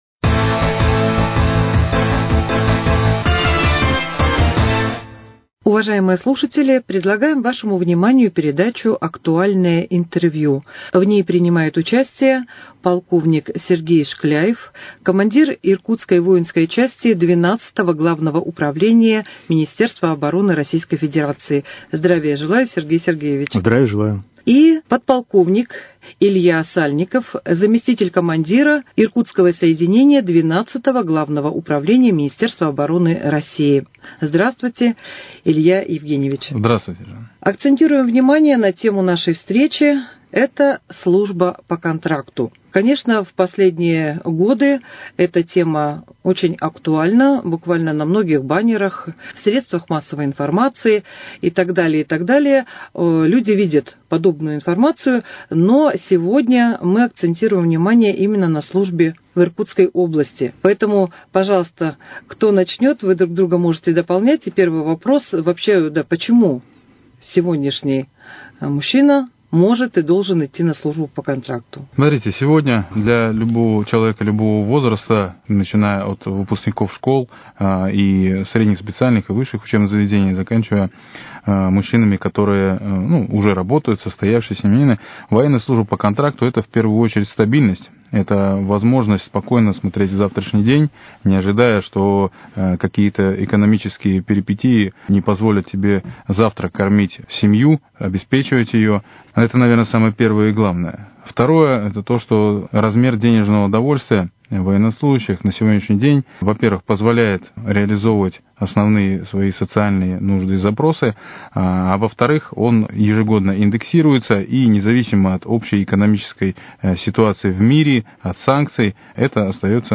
Актуальное интервью: Служба по контракту в 12 ГУМО Иркутской области